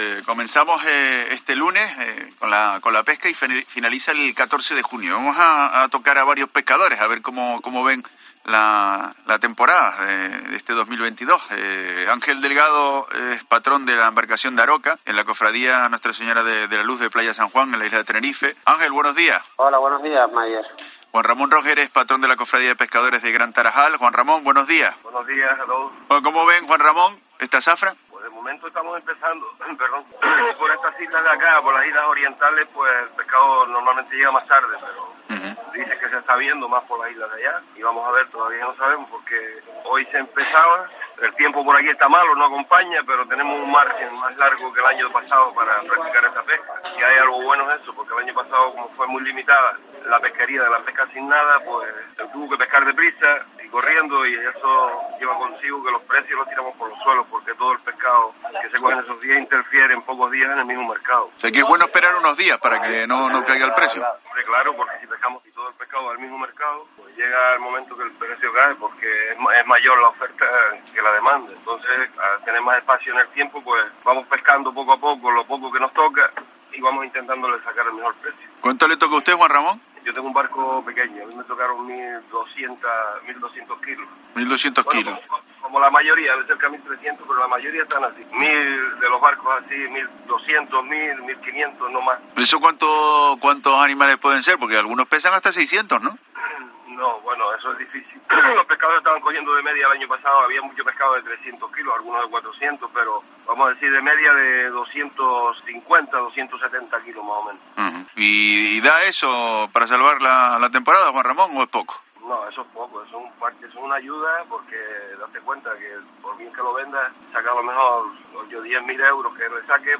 Patrones de las Cofradías de Pescadores en La Mañana en Canarias